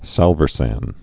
(sălvər-săn)